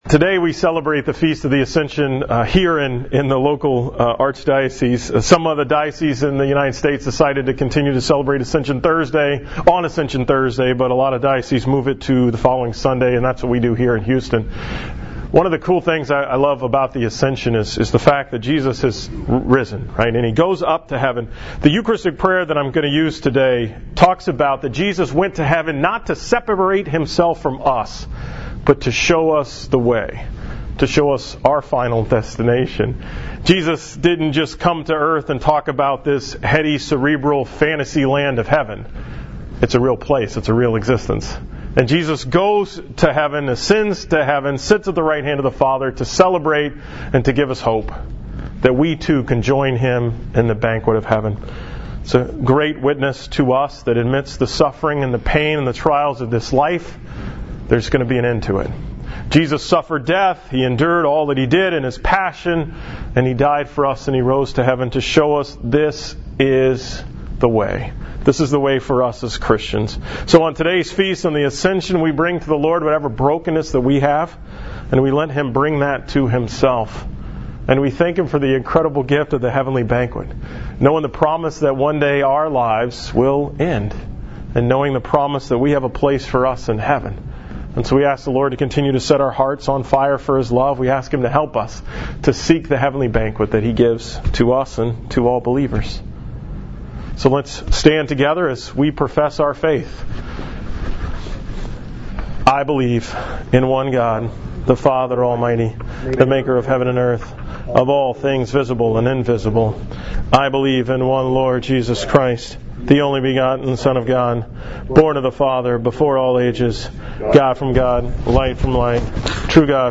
A very brief homily from Minute Maid park on Sunday, May 28, 2017